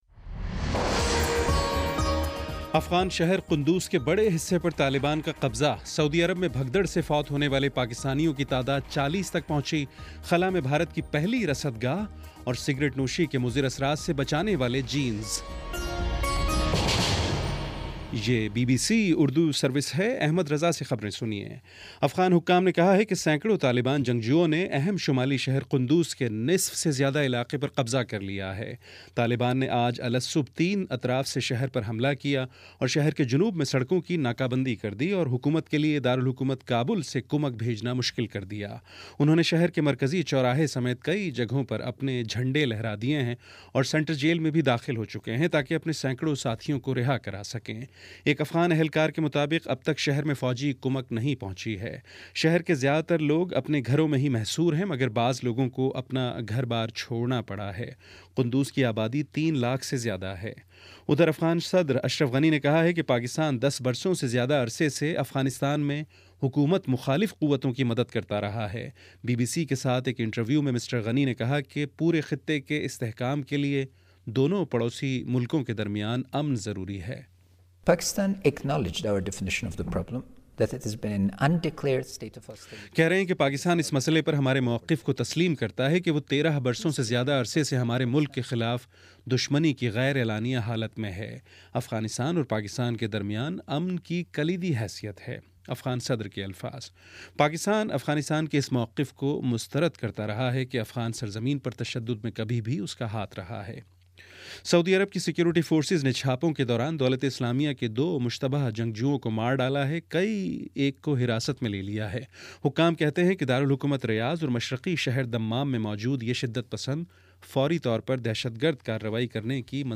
ستمبر28 : شام سات بجے کا نیوز بُلیٹن